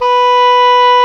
WND OBOE B4.wav